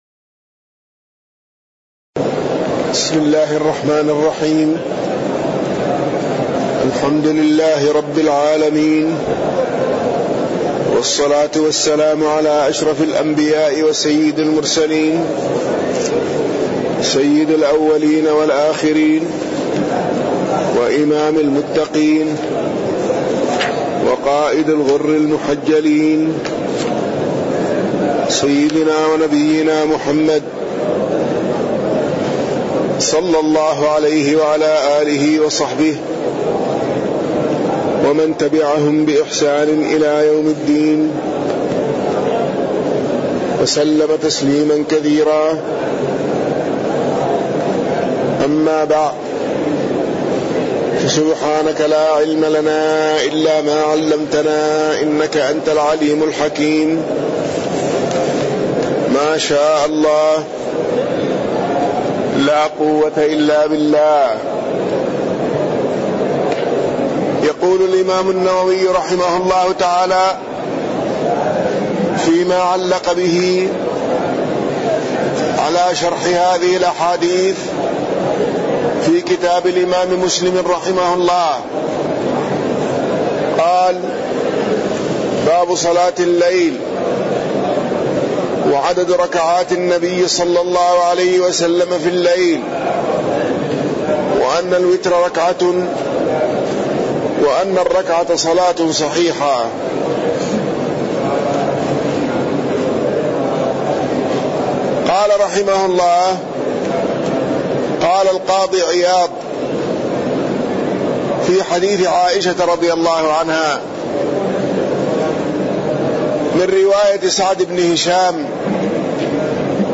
تاريخ النشر ١٩ رمضان ١٤٣٠ هـ المكان: المسجد النبوي الشيخ